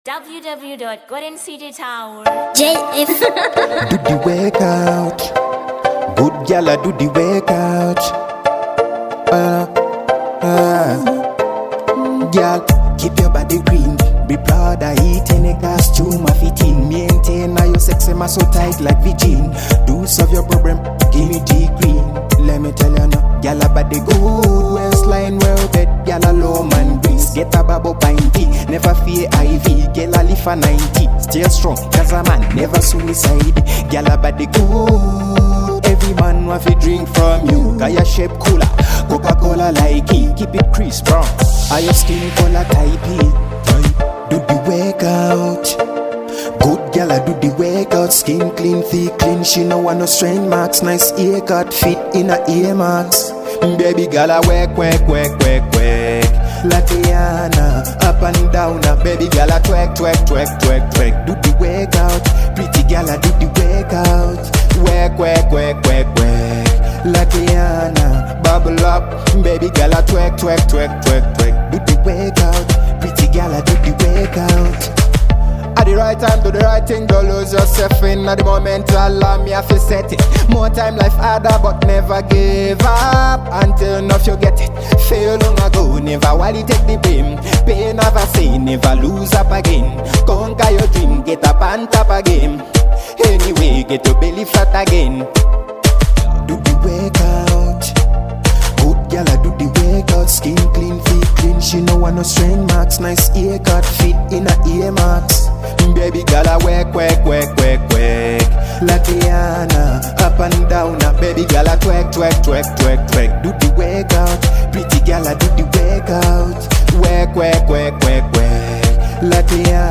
2. Dancehall